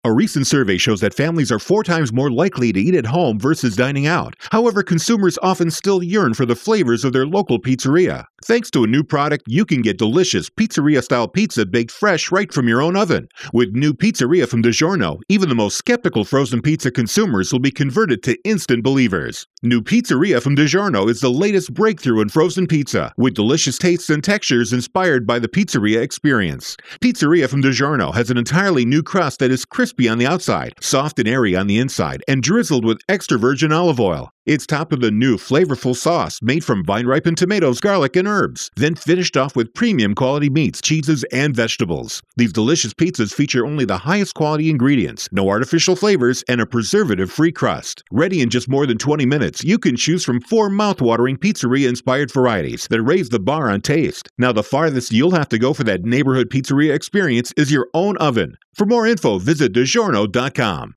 April 9, 2013Posted in: Audio News Release